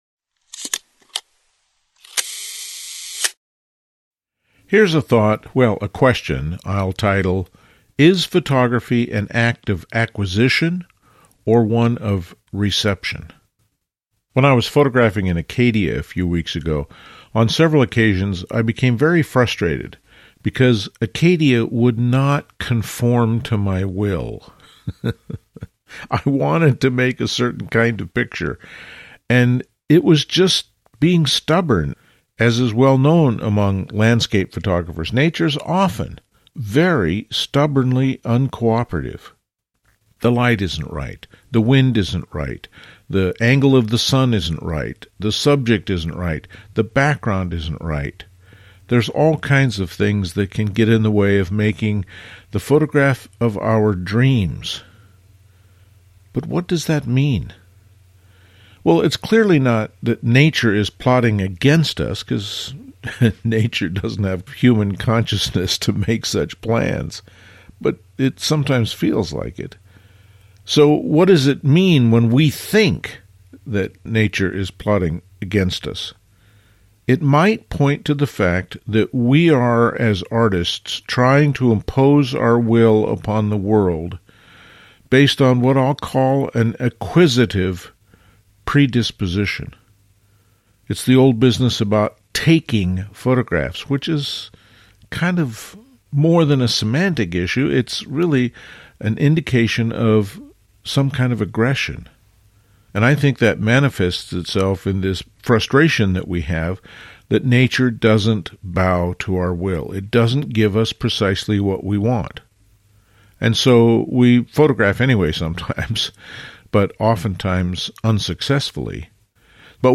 These talks focus on the creative process in fine art photography.